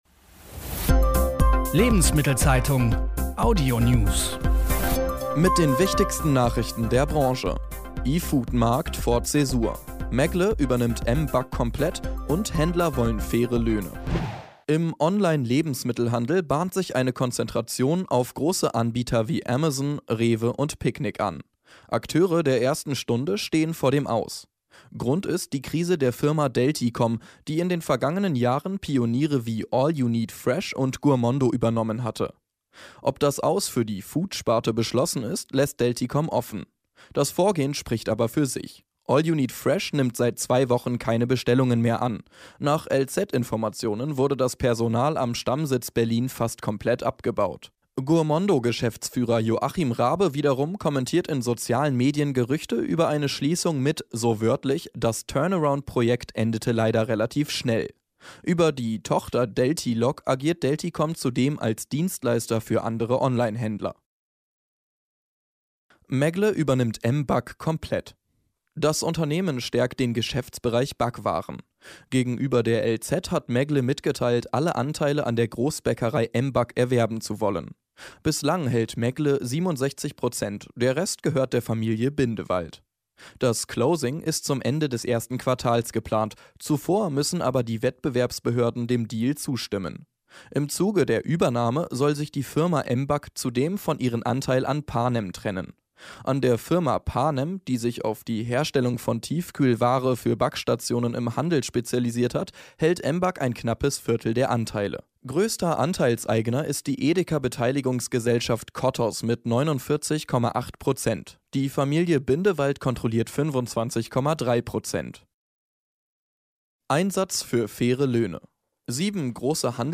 Die wichtigsten Nachrichten aus Handel und Konsumgüterwirtschaft zum Hören